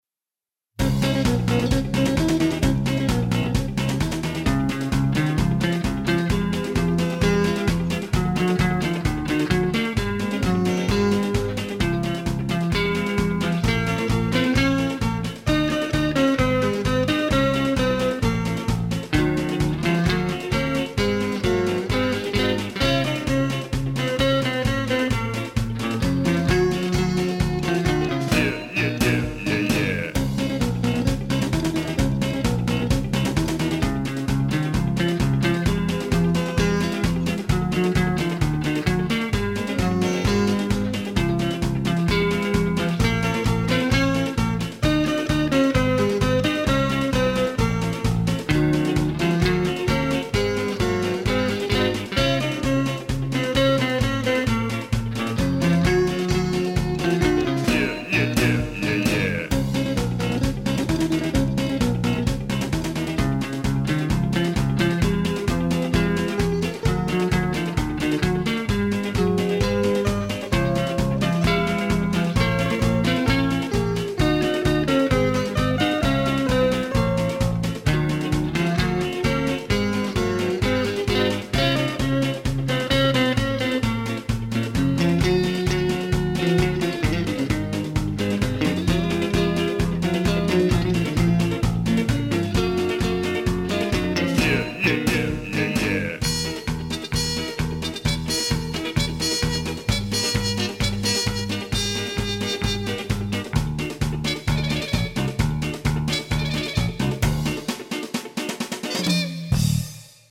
минусовка версия 226099